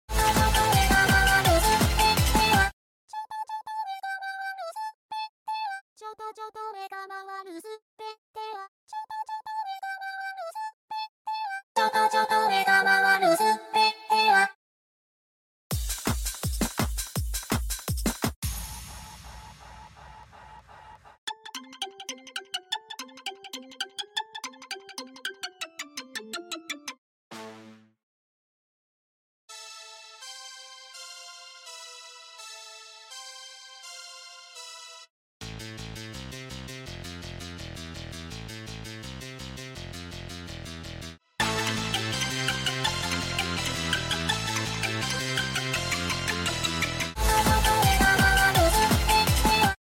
Vocaloid song